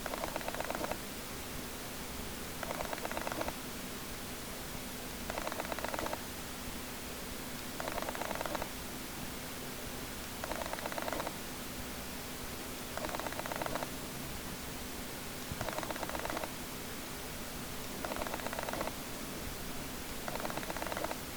Canon 5D Mk III kontraszt AF állításának hangja (MP3) élőkép módban.
canon_af_contrast.mp3